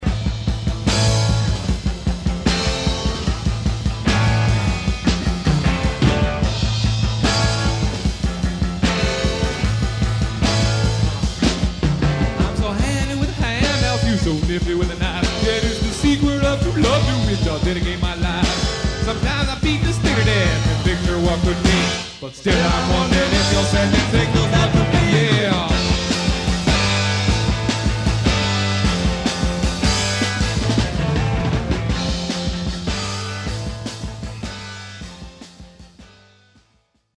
(Live Studio Demo)
drums
lead guitar, harmony vocals
bass, harmony vocals
Electric 12-string guitar, lead vocals